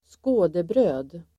Folkets service: skådebröd skådebröd substantiv (även bildligt), shewbread , showbread Uttal: [²sk'å:debrö:d] Böjningar: skådebrödet, skådebröd, skådebröden Definition: bröd som inte skall ätas utan bara ses på